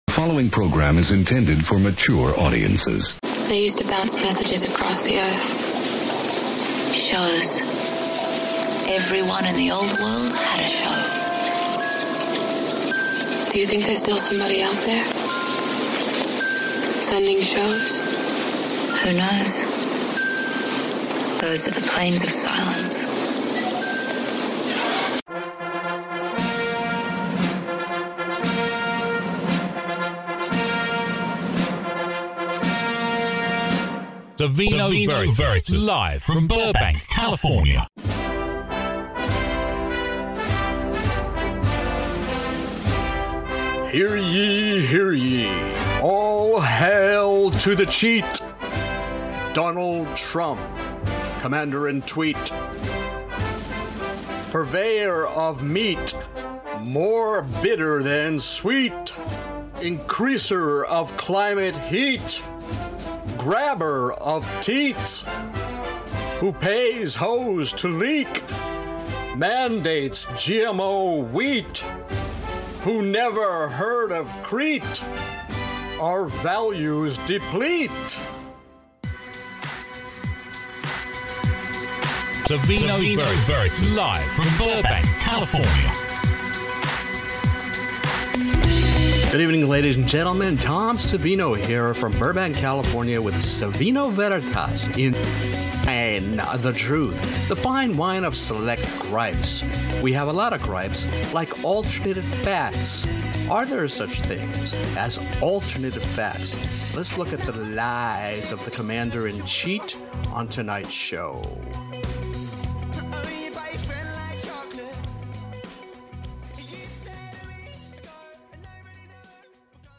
Billy Eichner escorts Stephen Colbert on a NYC street asking people what they’d like to say to Trump. Then Randy Rainbow bursts into a musical out of frustration with alternate facts while trying to get the truth from Kellyanne Conjob.